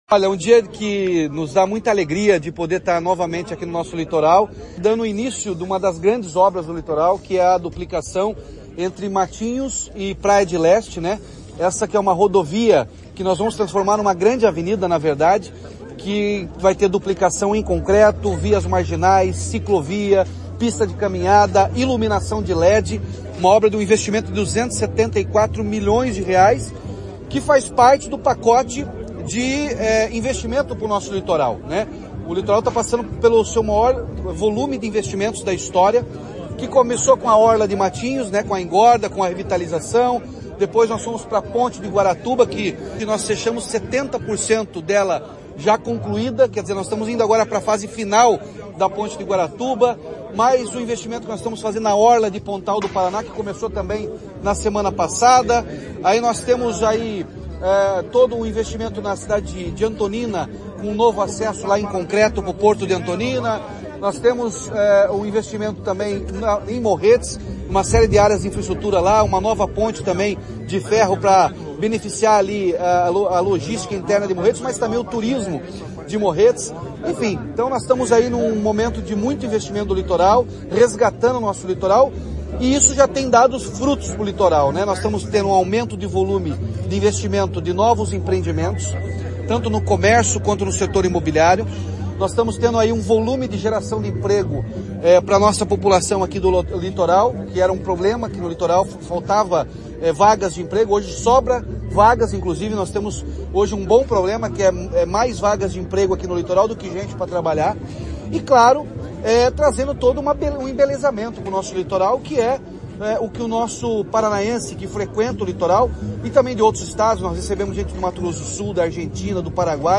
Sonora do governador Ratinho Junior sobre início da duplicação em concreto da PR-412 entre Matinhos e Pontal do Paraná